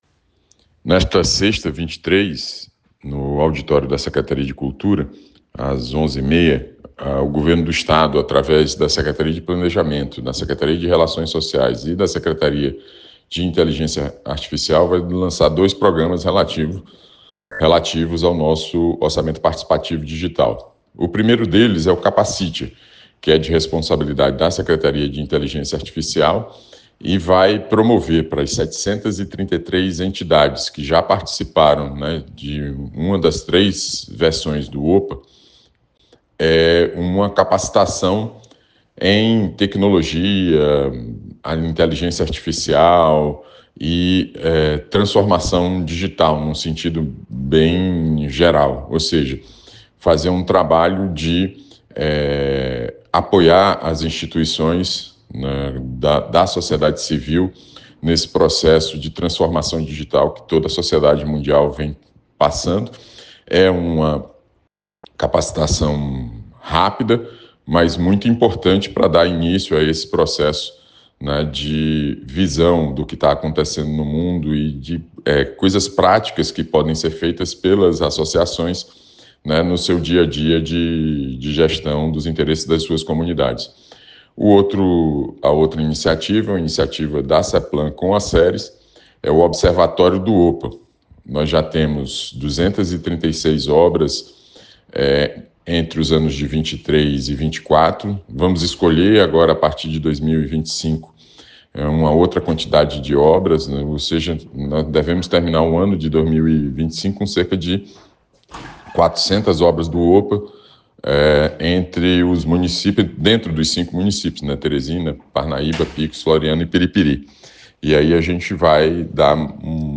Secretário de Planejamento do Piauí, Washington Bonfim, em entrevista ao Piauí Hoje